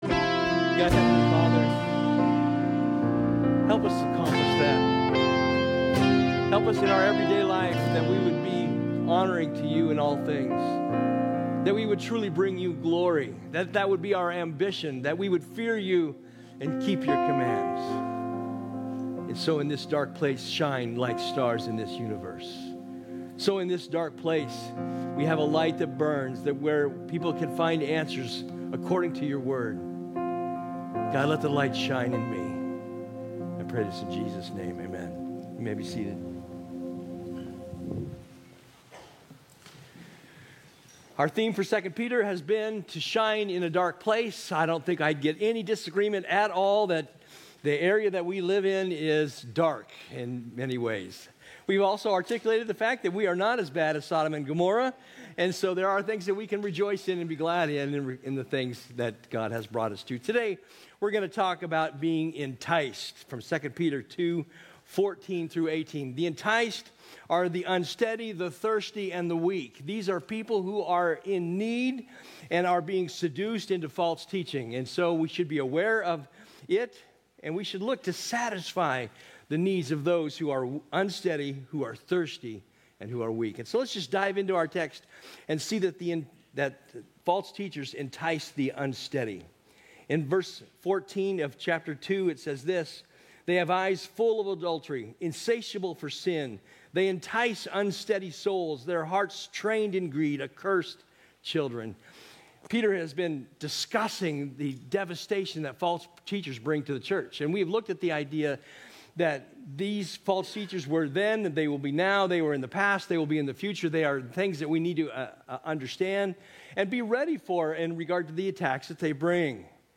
A message from the series "Shine In A Dark Place."